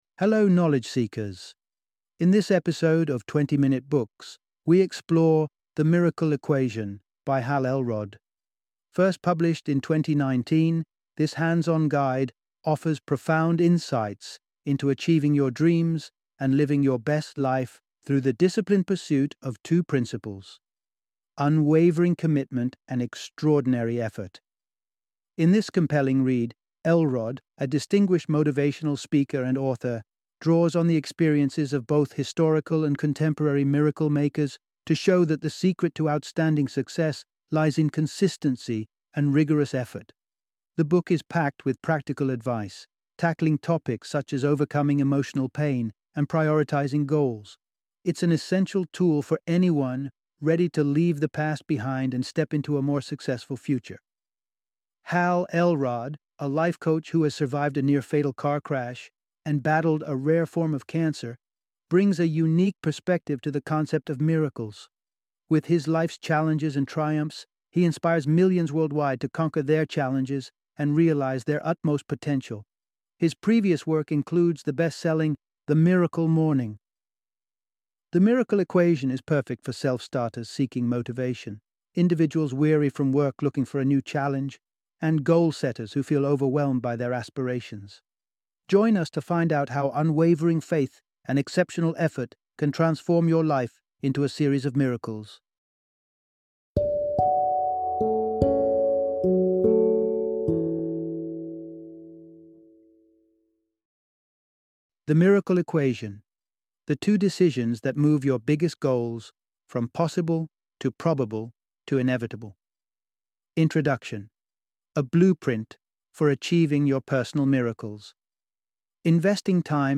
The Miracle Equation - Audiobook Summary